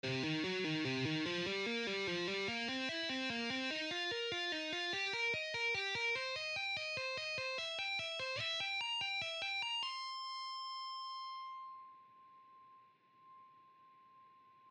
Lesson 4: Cm pentatonic Blues Scale
40% Speed:
Exercise-4-slow-Cm-Pentatonic-blues-scale-1.mp3